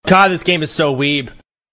applause1.mp3